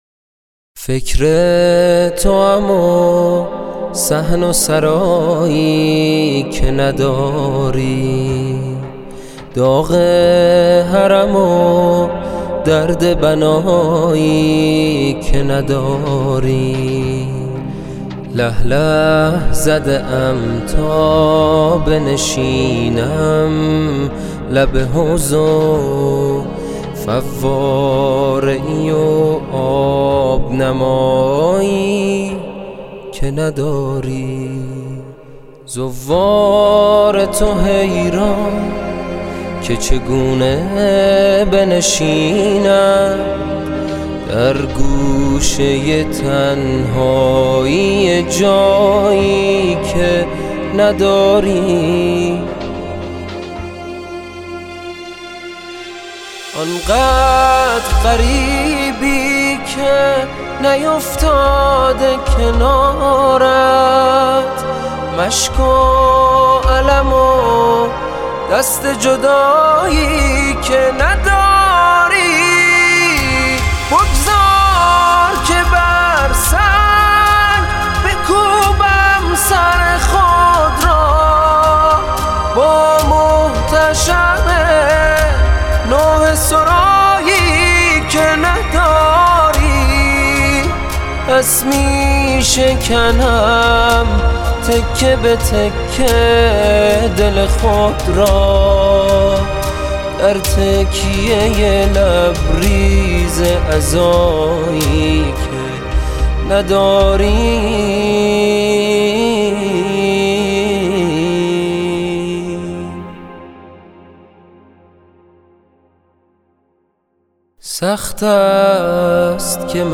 کلیپ عزاداری